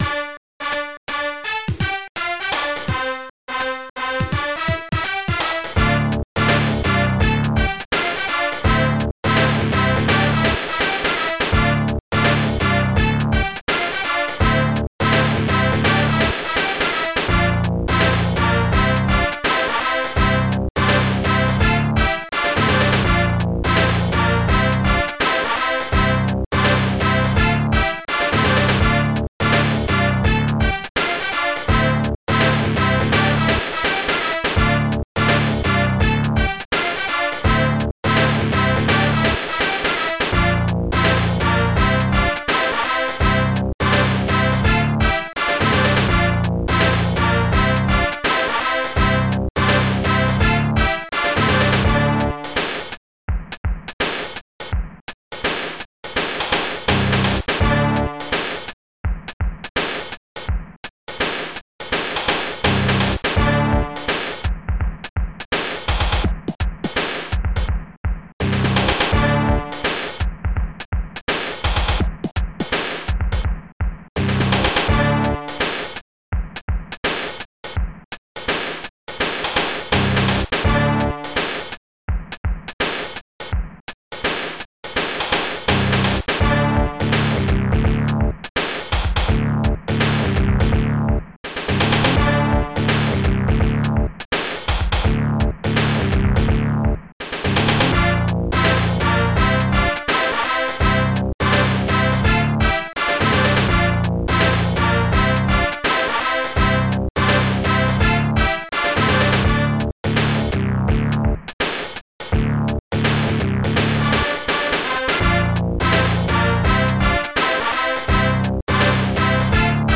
Protracker Module
st-12:acidbass ST-01: ST-01: st-01:bassdrum st-01:hihat1 st-01:hihat2 st-01:snaredrum st-01:trompet